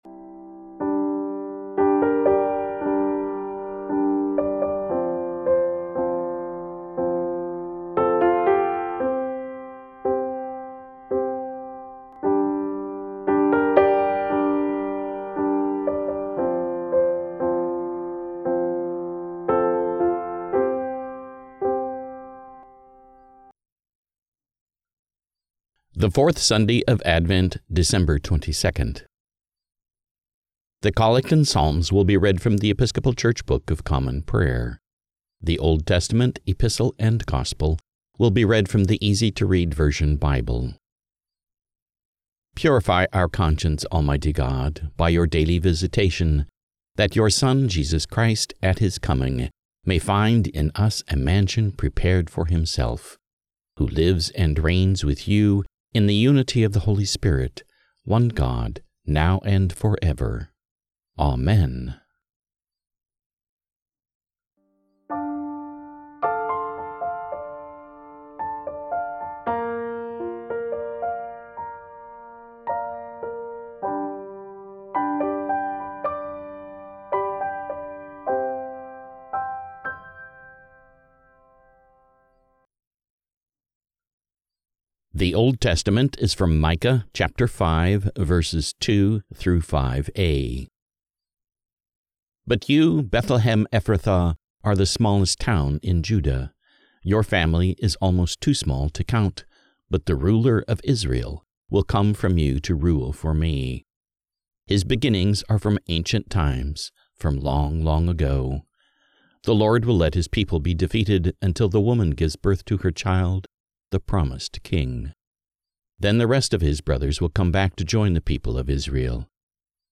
The Collect and Psalms will be read from The Episcopal Church Book of Common Prayer
The Old Testament, Epistle and Gospel will be read from The Easy to Read Version Bible.